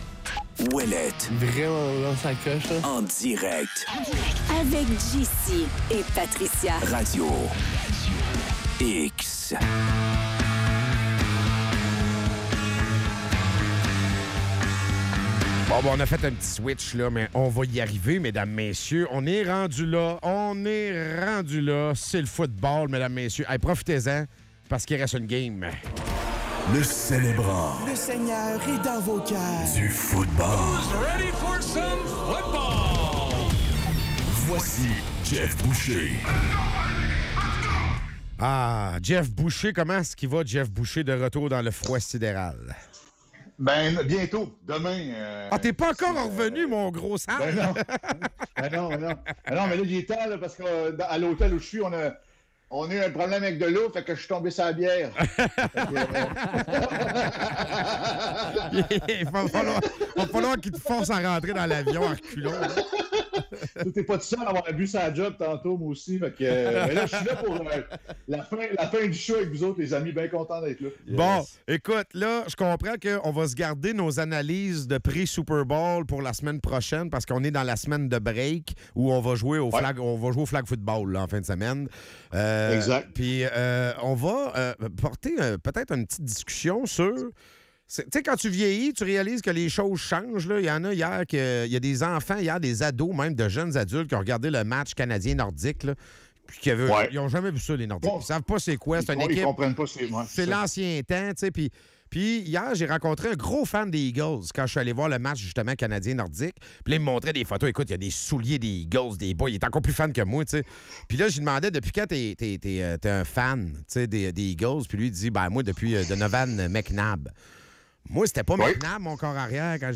Bill Belichick ne sera pas intronisé au Hall of Fame à sa première année d'éligibilité, et cela suscite de nombreuses interrogations. Les animateurs analysent les changements dans le monde du sport, notamment les mouvements de coachs et l'évolution des fans.